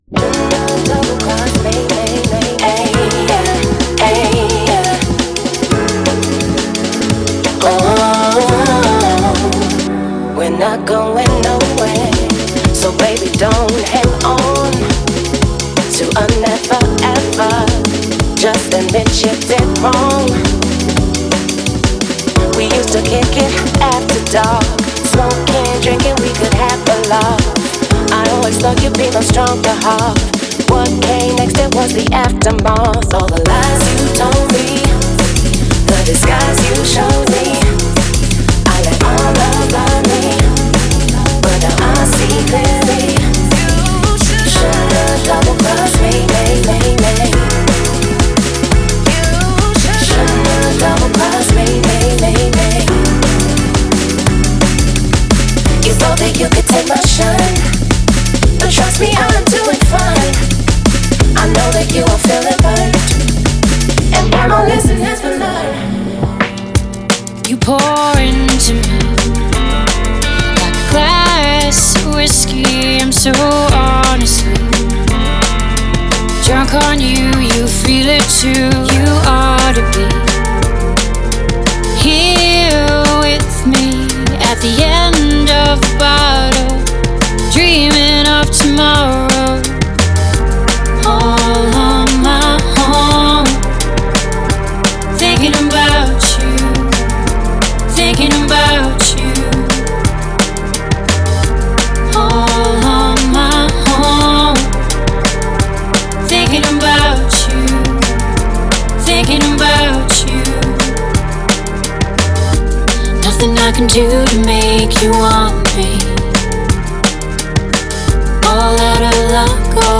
Genre:Liquid
アドリブ、メインハーモニー、アンサンブルレイヤー、ダブルボーカルを自由に組み合わせてミックスすることができます。
最大限の創造的自由を確保するために、すべてのボーカルにはドライバージョンとウェットバージョンの両方を収録しています。
この組み合わせにより、サウンドに温かみと明瞭さが注ぎ込まれています。
3 Vocal Song Kits